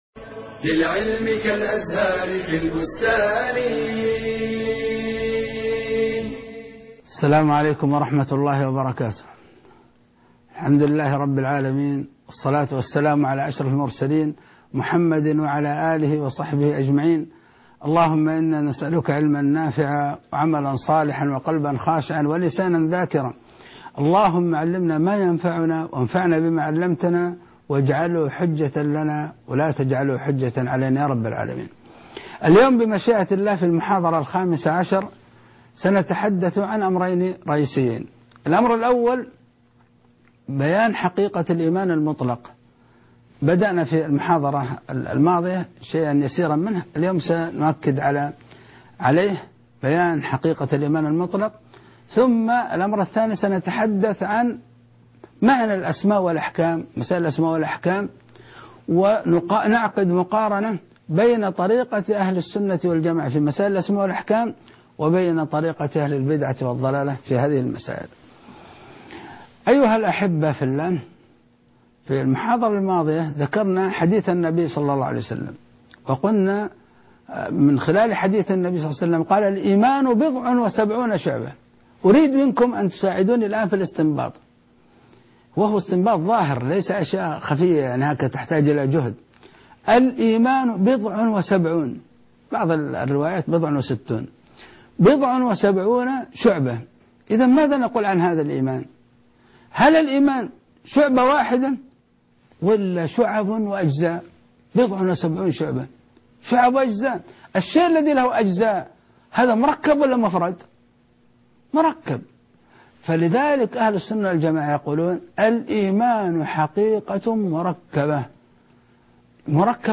المحاضرة الخامسة عشر _ حقيقة الايمان_